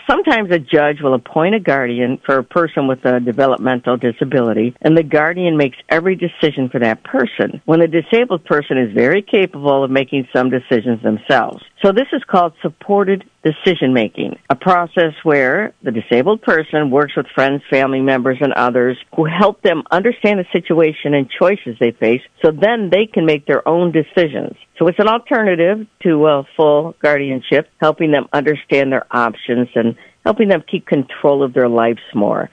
This full conversation is available to listen to and download on the local interviews portion of our website. State Representative Kathy Schmaltz regularly joins A.M. Jackson on Fridays.